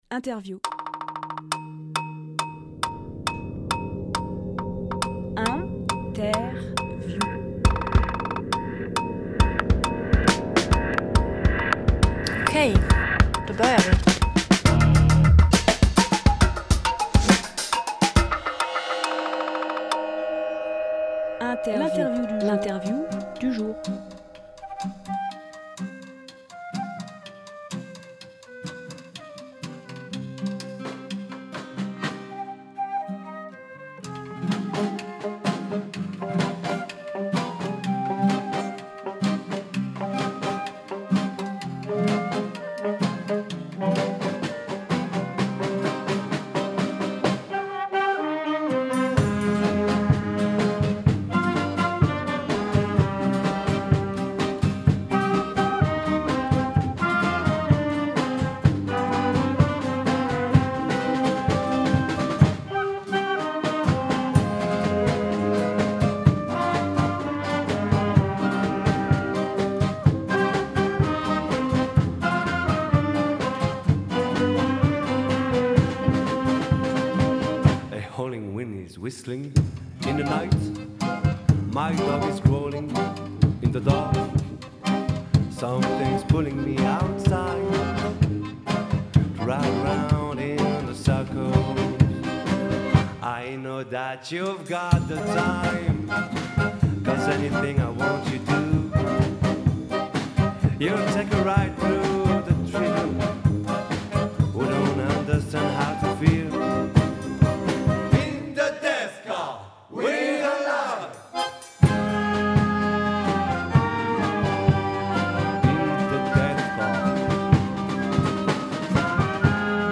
Emission - Interview Les Oiseaux De Trottoir Publié le 24 octobre 2022 Partager sur… Télécharger en MP3 Les oiseaux de trottoir sont quinze musiciens, professionnels ou amateurs qui sillonnent les routes de la Drôme pour une tournée avec le parti pris de la faire en vélo.
22.10.22 Lieu : Studio RDWA Durée